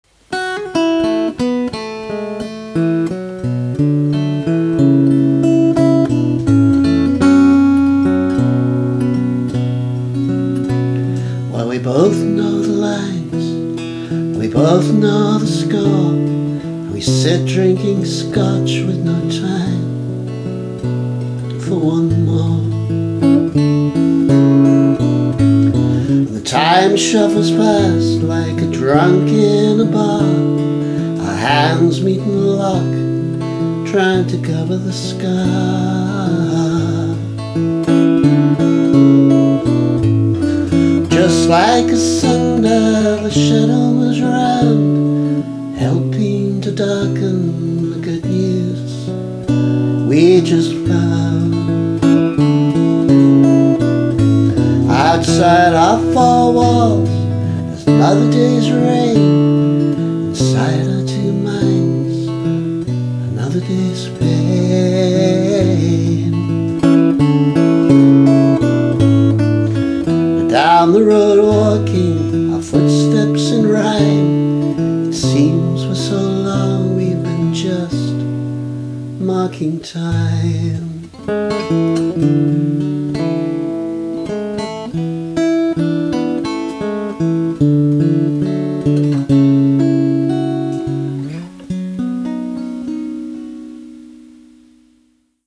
alternative take: